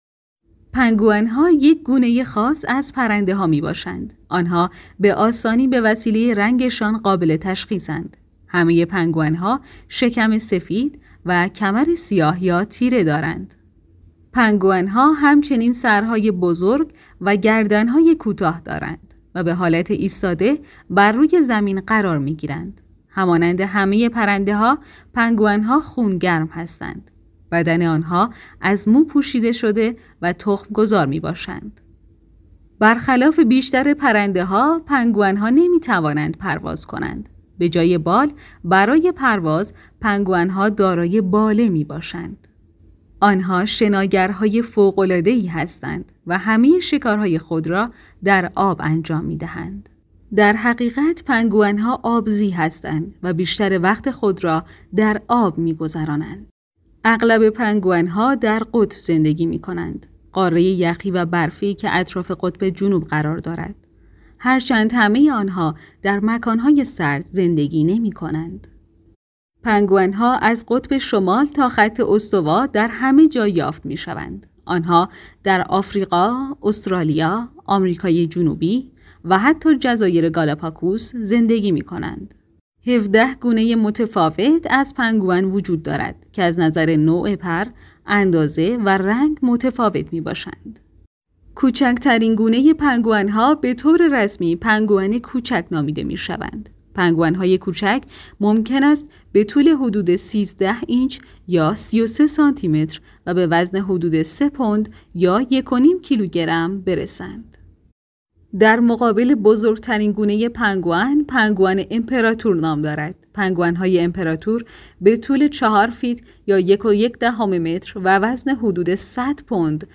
گوینده